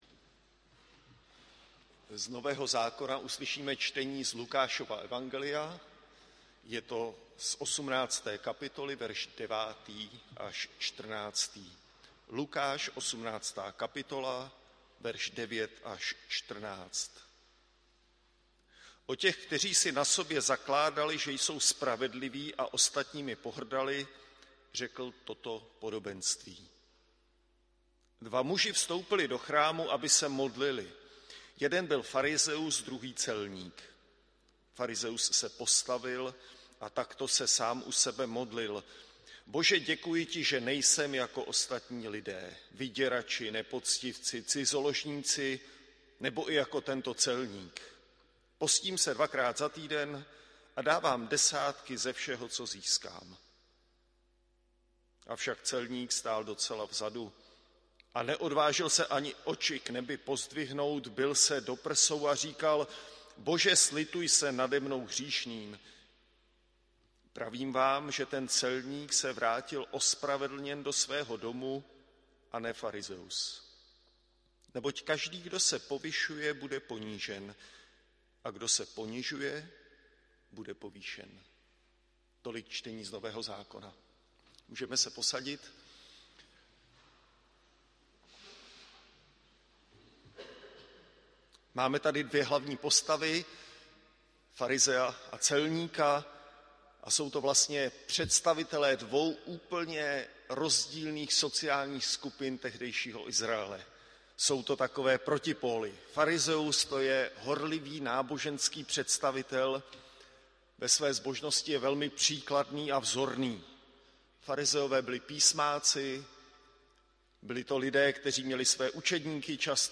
audio kázání